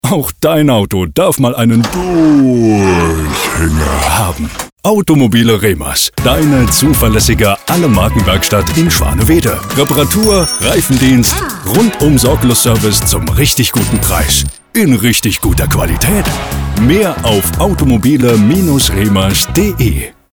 Dynamisch